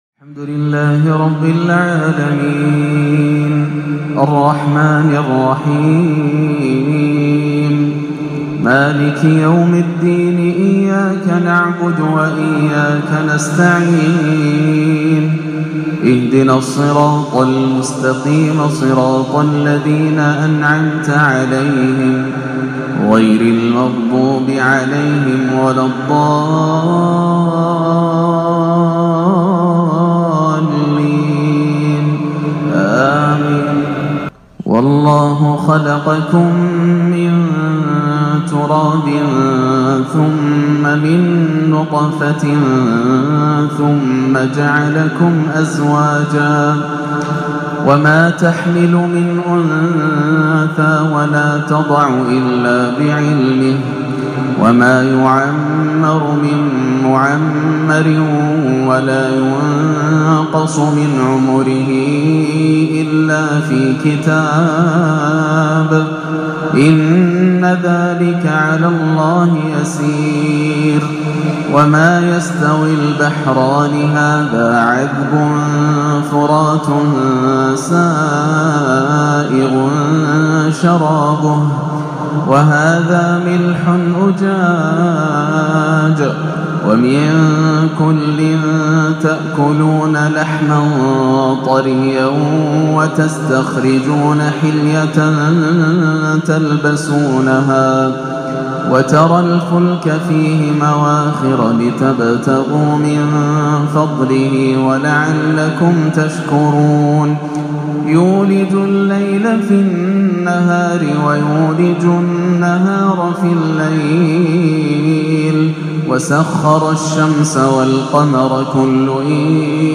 تلاوة كردية حزينة من سورة فاطر - الأربعاء 6-4-1438 > عام 1438 > الفروض - تلاوات ياسر الدوسري